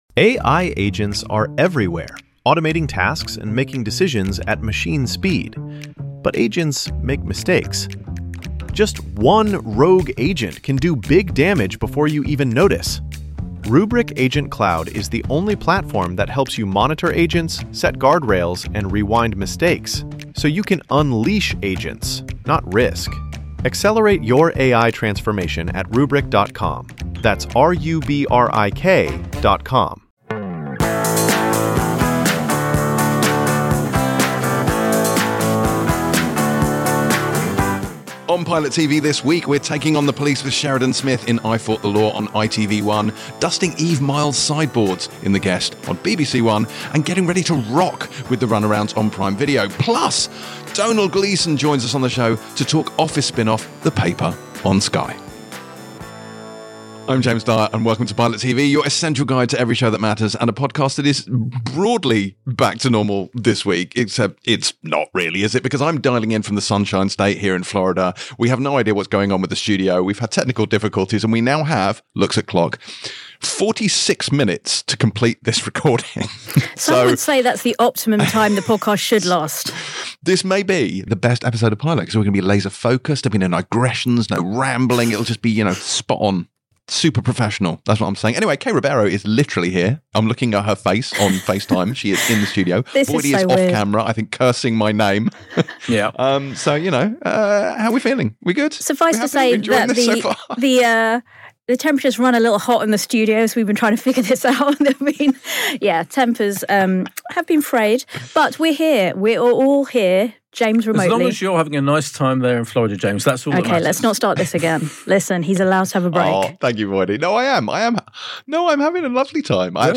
Click to listen, free! 459 episodes in the TV Reviews genre.